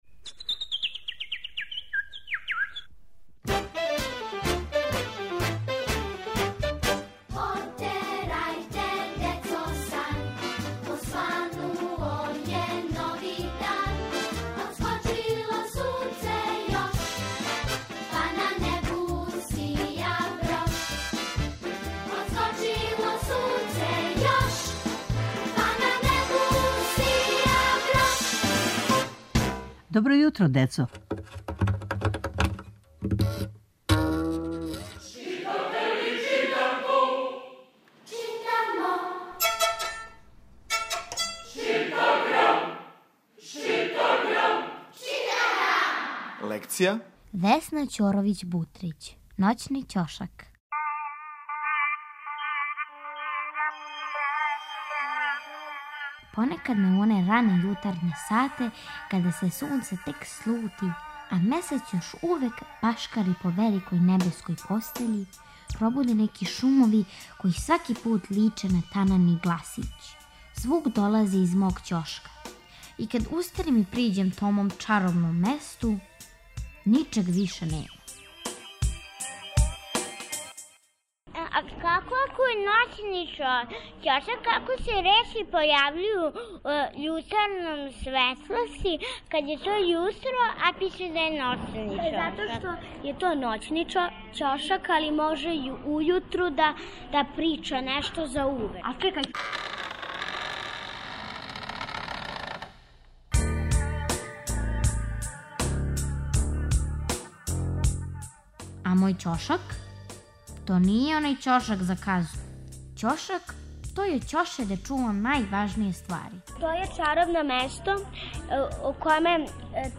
Сваког понедељка у емисији Добро јутро, децо - ЧИТАГРАМ: Читанка за слушање.